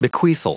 Prononciation du mot bequeathal en anglais (fichier audio)
Prononciation du mot : bequeathal